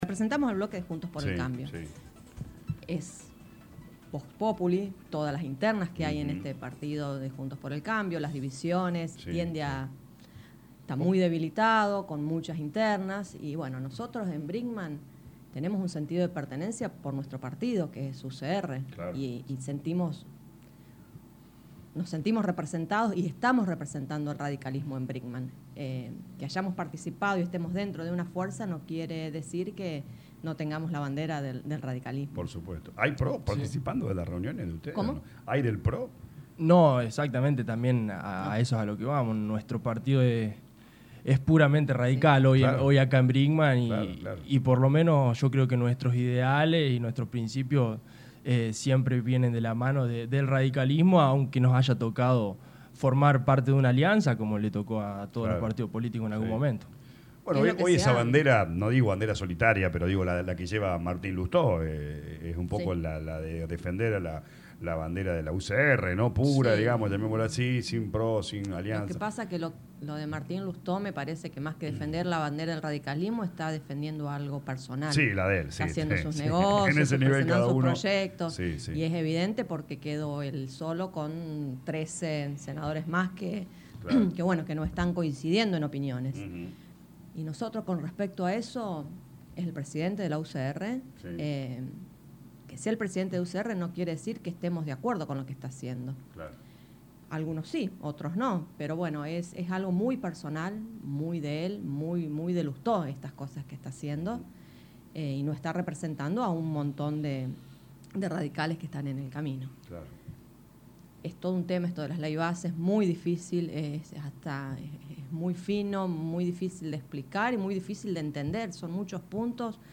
De visita a los estudios de LA RADIO 102.9 FM los concejales Mariela Tavano e Hipólito Tomati informaron que en el mes de septiembre se realizarán las elecciones de autoridades partidarias de la Unión Cívica Radical-Brinkmann.